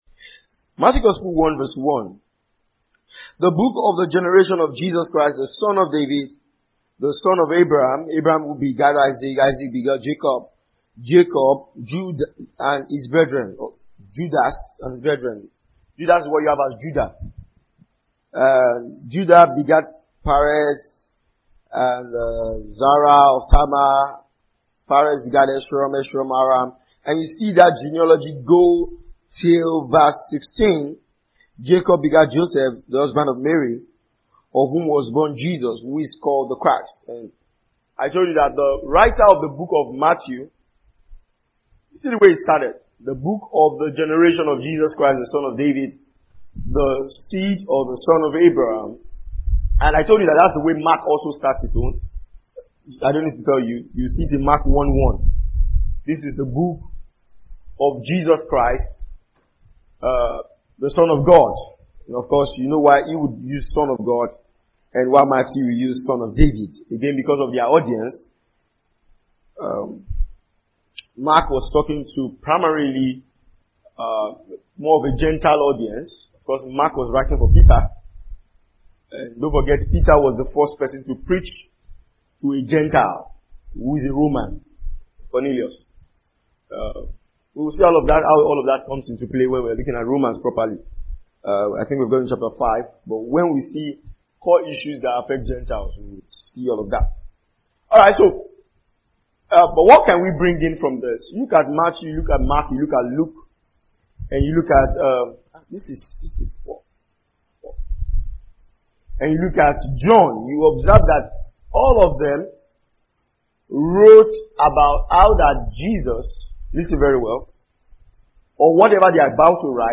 A message that highlights the place of discipleship in the believer’s journey. It examines the biblical standard of discipleship and how it has to do with single-facedness.